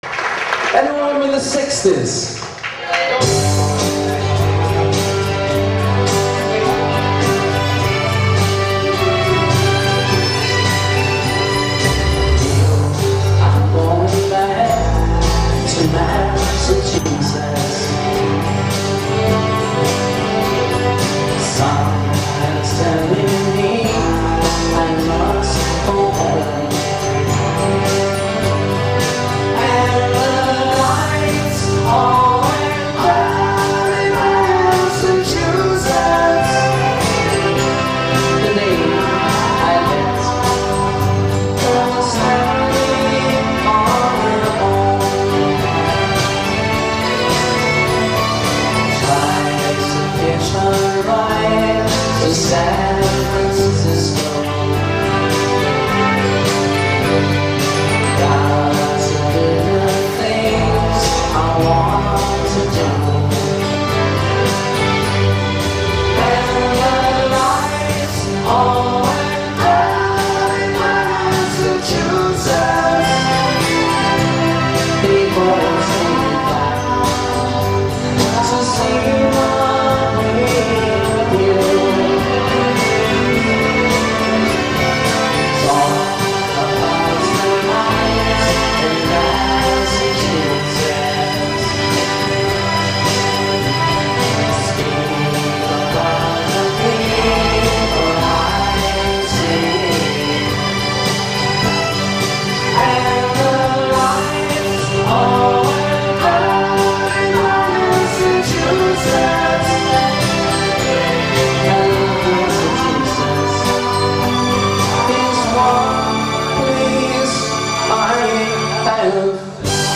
His unique falsetto voice